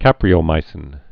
(kăprē-ō-mīsĭn)